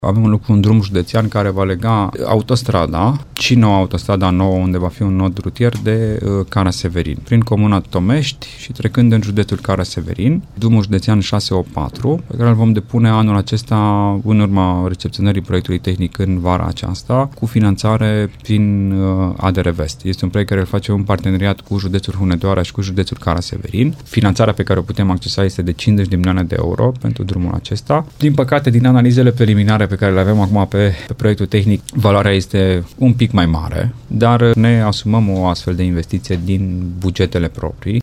Termenul de finalizare al proiectului este vara acestui an, după care va fi depusă o cerere de finanțare din fonduri europene, spune vicepreședintele CJ Timiș, Alexandru Iovescu.